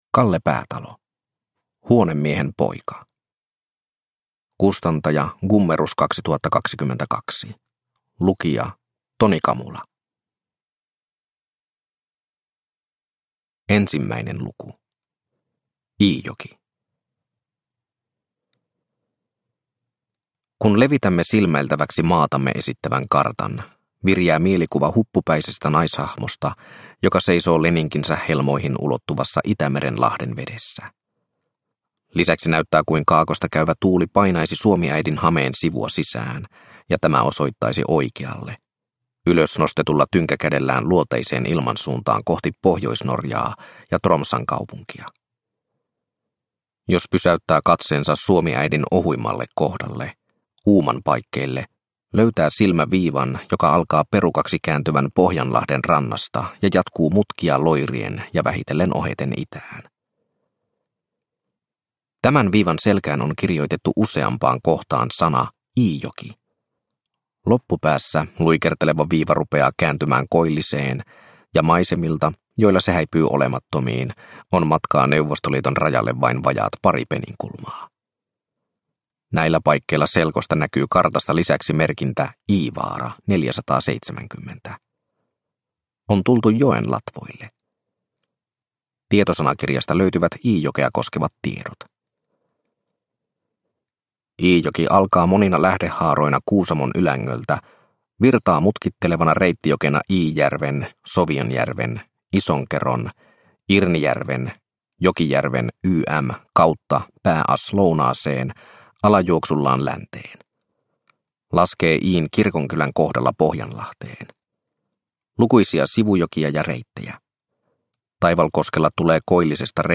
Huonemiehen poika – Ljudbok – Laddas ner